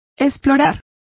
Complete with pronunciation of the translation of explore.